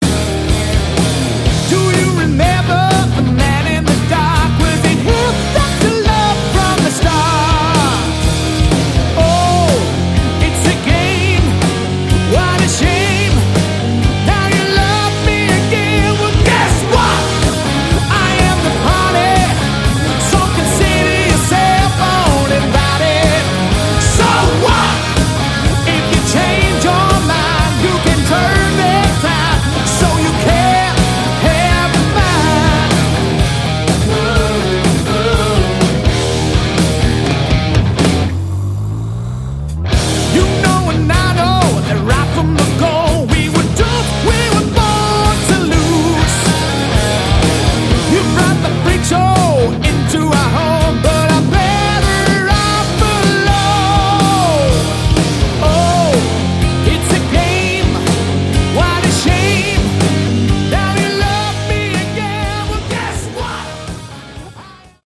Category: Hard Rock
lead vocals
guitar
bass
drums
More like modern hard rock.